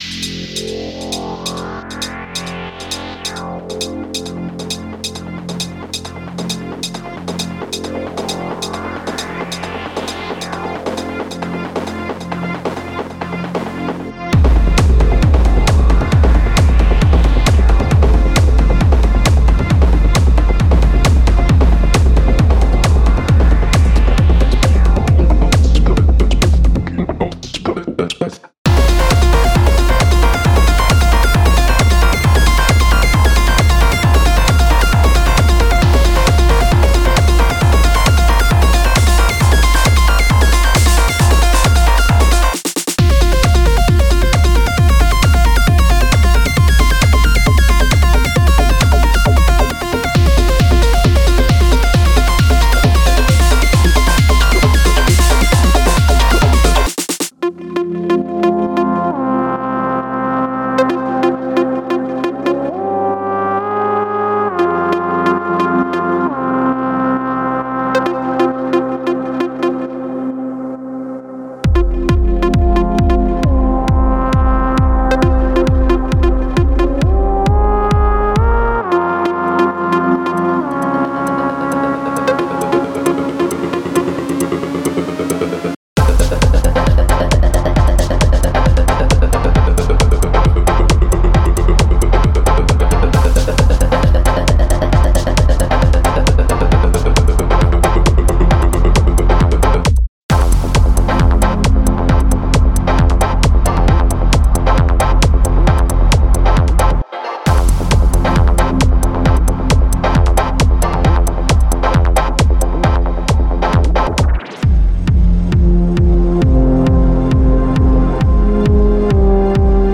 Genre:Techno
テクノのよりダークで催眠的な側面に大きくフォーカスし、インダストリアルな重厚さと差し迫る破滅の感覚を融合しています。
デモサウンドはコチラ↓
10 303 Acid Loops
08 Chopped Vox Loops